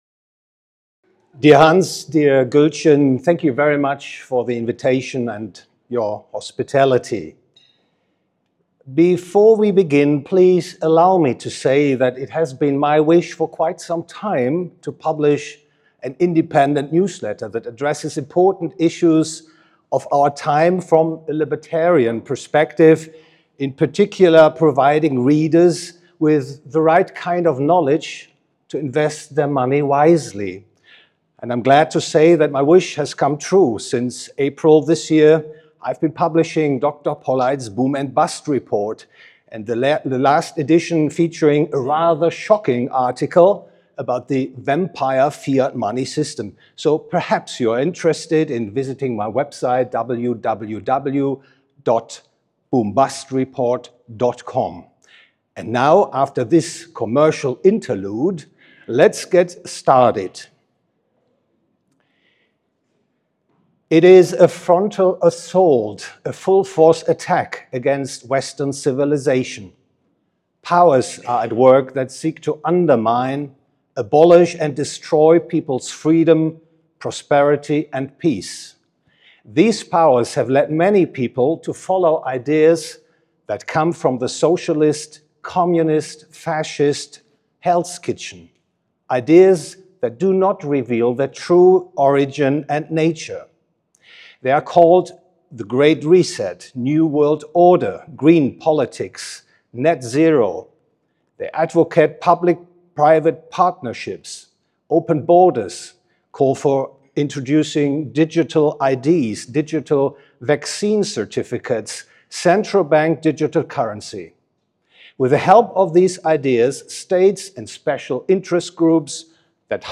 This talk is from the 18th annual 2024 Annual Meeting of the PFS (Sept. 19–24, 2024, Bodrum, Turkey).